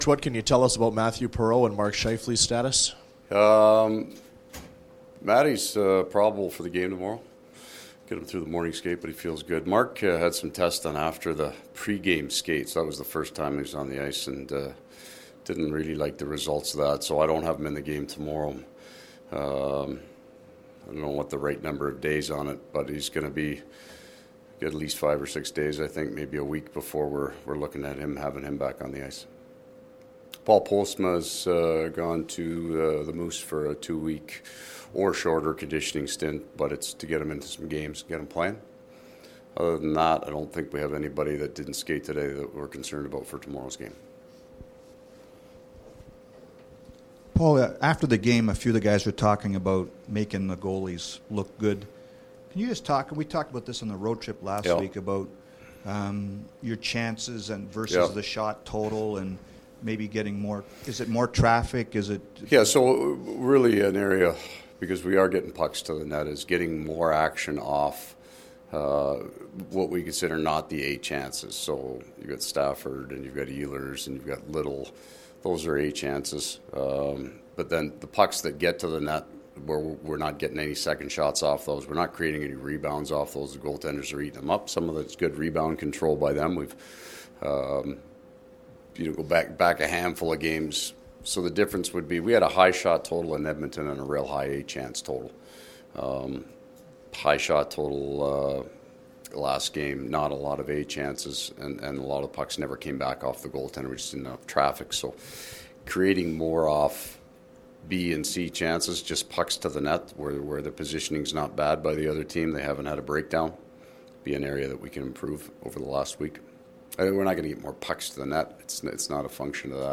Winnipeg Jets coach Paul Maurice scrum
Coach Maurice’s post practice comments.